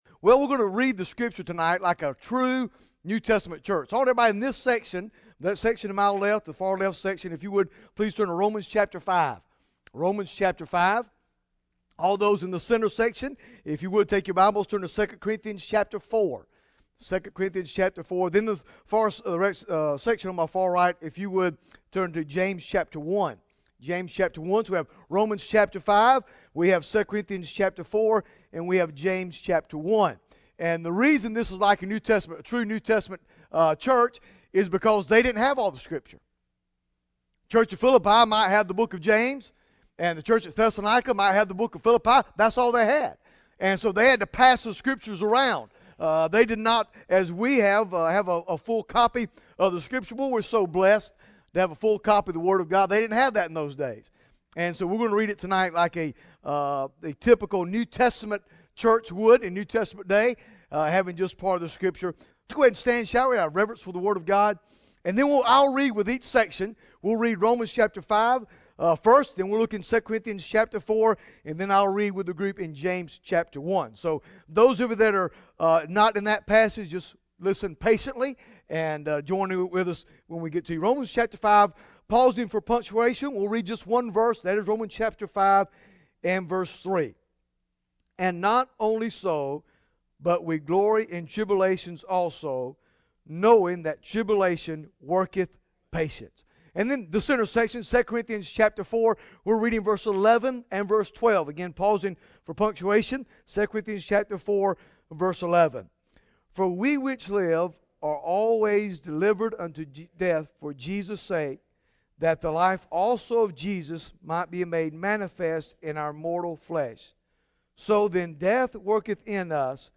Bible Text: II Corinthians 12 | Preacher